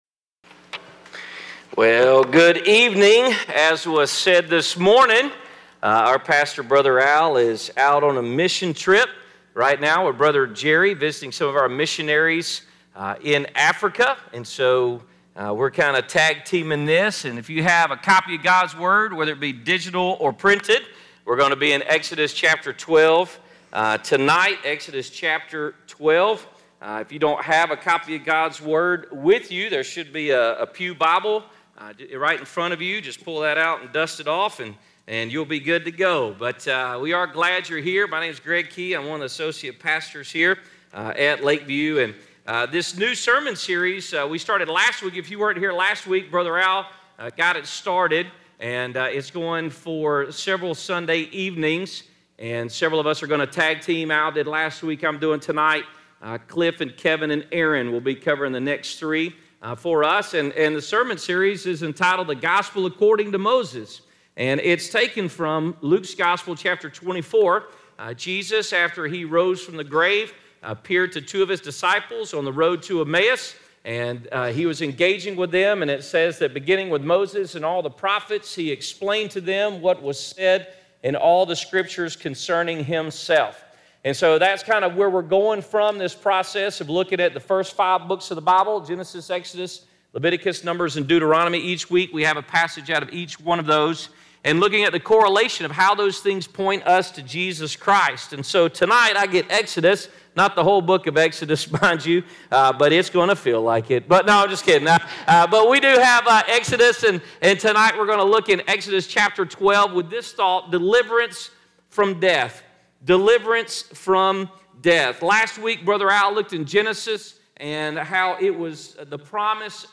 Exodus 12:1-30 Service Type: Sunday Evening 1.